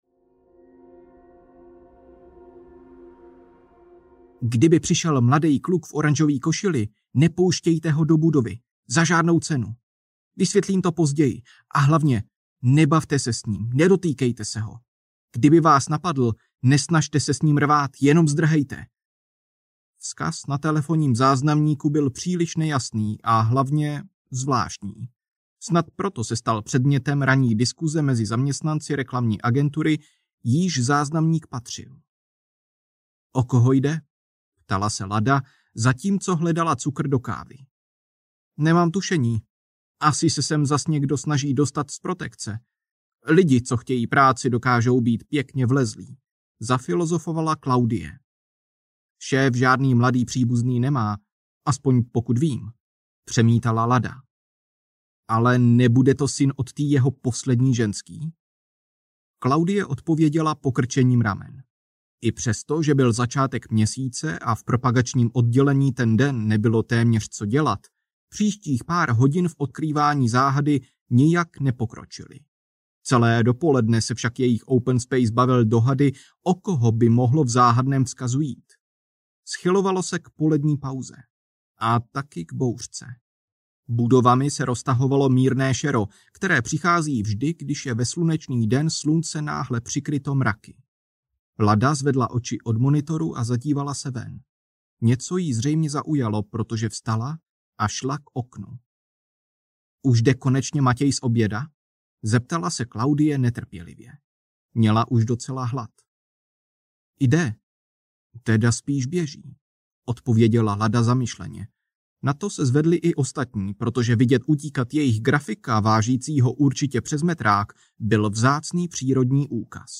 Kategorie: Fantasy, Sci-fi, Povídkové